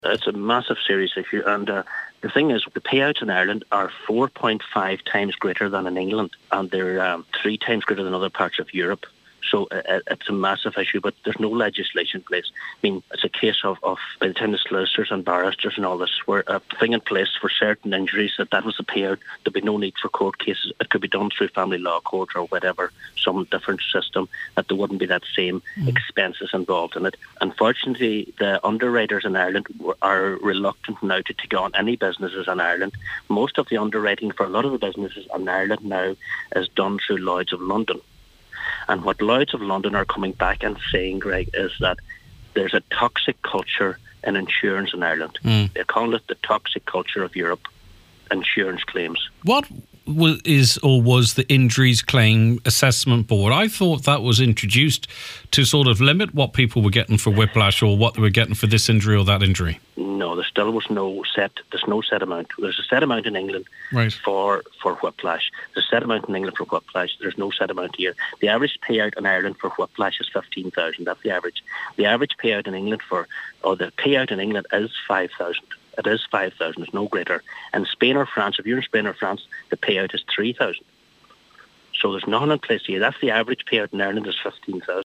Councillor Harley told today’s Nine til Noon Show that businesses are being severely impacted: